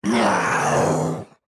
evil-turkey-v1.ogg